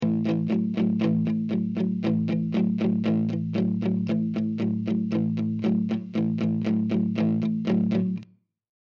Apesar do riff ser baseado em colcheias, usar a palhetada alternada não se mostrava tão apelativa como palhetadas só para baixo, e isto resulta num som mais agressivo e autentico.
Passamos agora para o acorde de Lá.
Ritmo de Rock 2
A7 guitarraTodo o exemplo é igual mas tocamos uma corda abaixo.